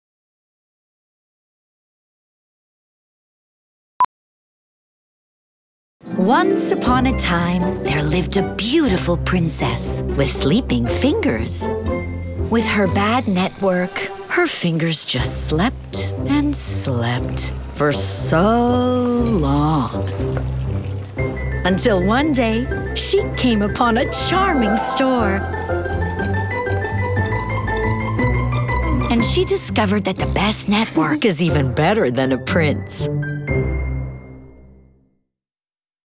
Commercial (Bell) - EN